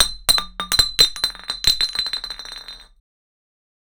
Falling Bottle on Floor 01
Bottle_falling_floor_1.wav